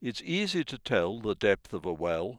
Sounds for Exercise I Chapter 4 spoken by a British Speaker